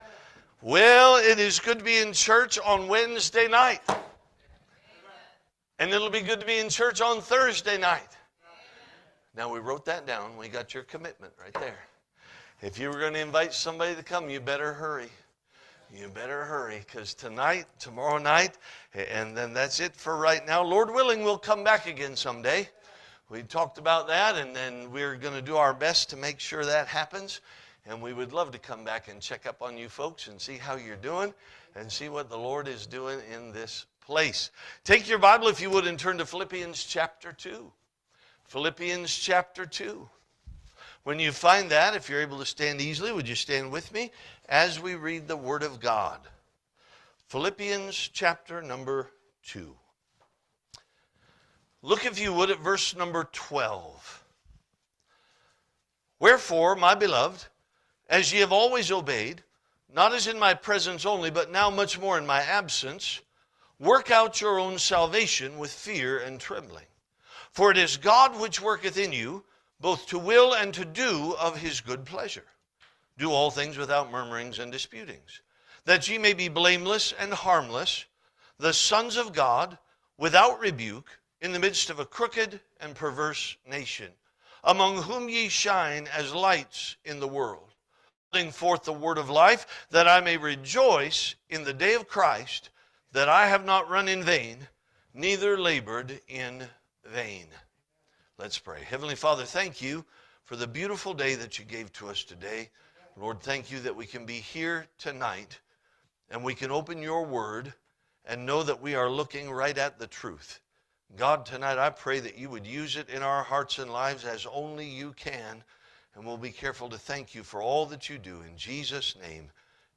Spring Revival 2026 Passage: Philippians 2 Service Type: Revival « Spring Revival 2026